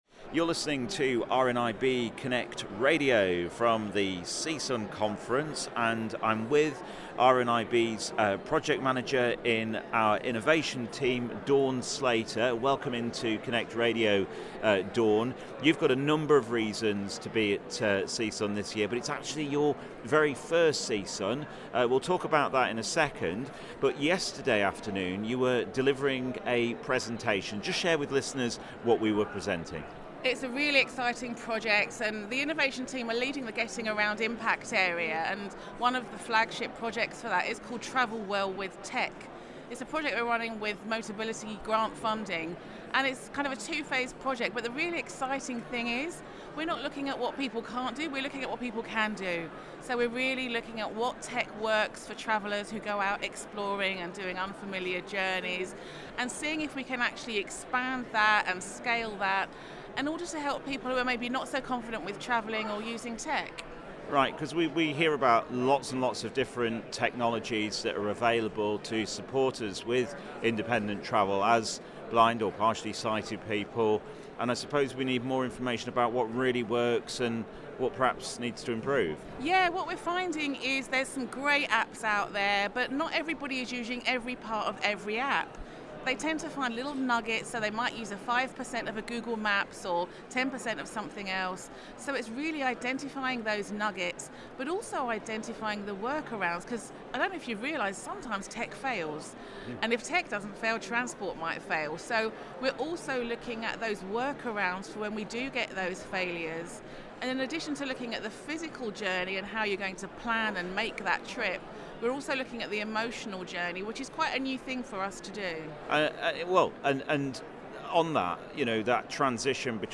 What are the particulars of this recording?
has been on the conference floor this year to speak to some of the delegates.